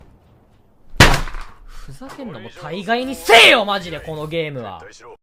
huzakerunomoda gai nisayyo Meme Sound Effect
Category: Movie Soundboard